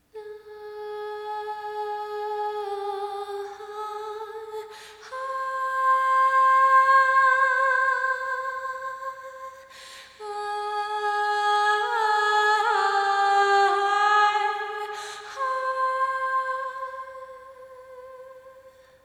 whimsical female vocal
Category 🗣 Voices
ambient ethereal female female-vocal singing vocal voice woman sound effect free sound royalty free Voices